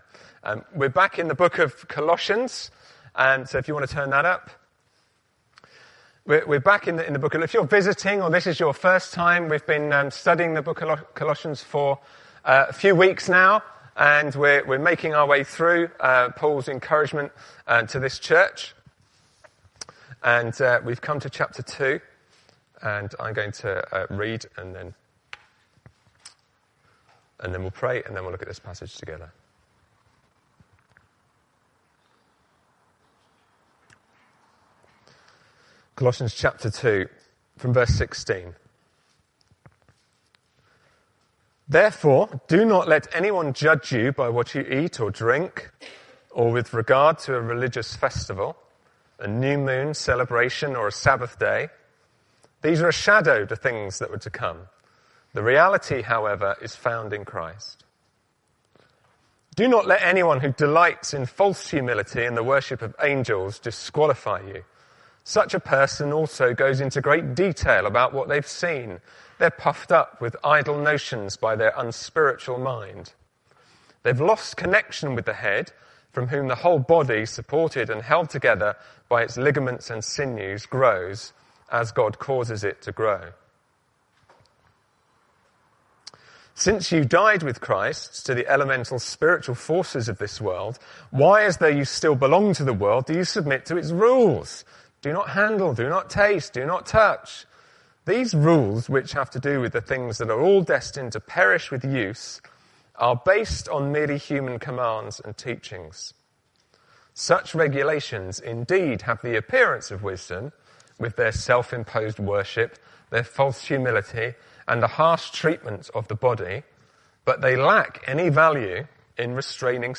Bradley Stoke Evangelical Church » Why Being in Jesus Makes Religion Needless